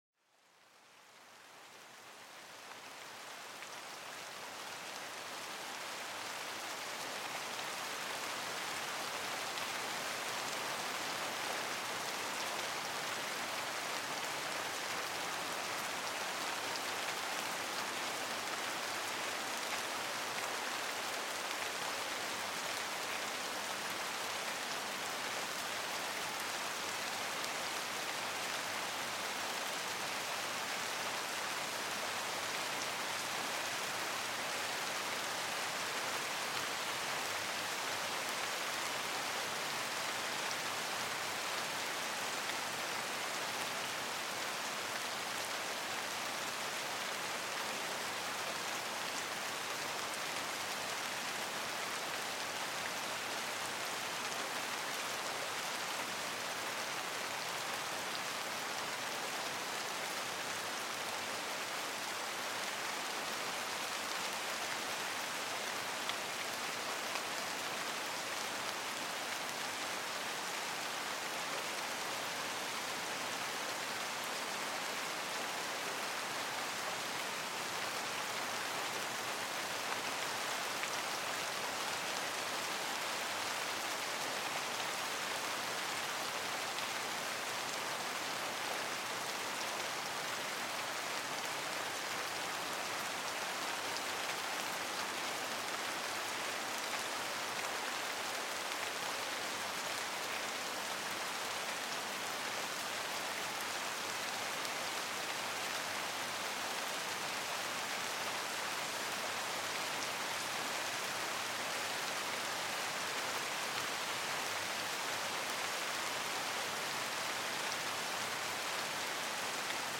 Serenidad Lluviosa: Relájate con el Suave Sonido de la Lluvia
Experimenta los beneficios calmantes de la lluvia suave, un sonido natural que tranquiliza la mente y favorece un sueño profundo. Sumérgete en un mundo sonoro donde cada gota de lluvia te lleva a una relajación más profunda.